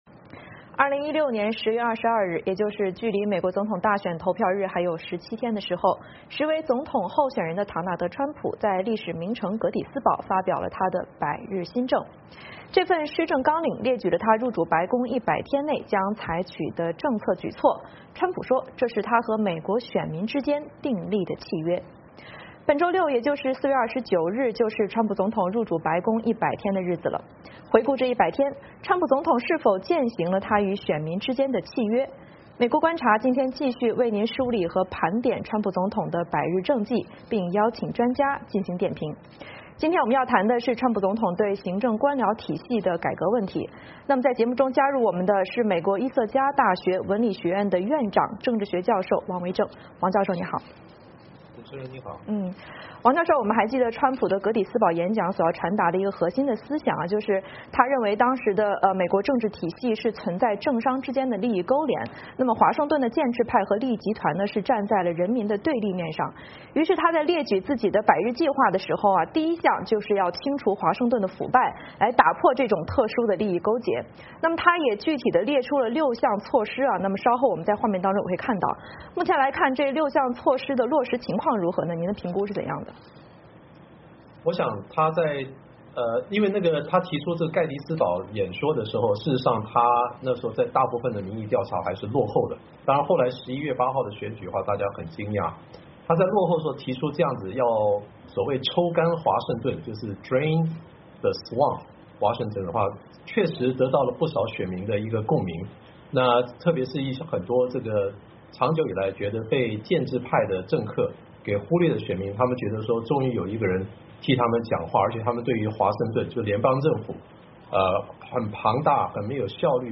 《美国观察》今天继续为您梳理和盘点川普总统的百日政绩，并邀请专家进行点评。今天我们要谈的是川普总统对行政官僚体系的改革问题。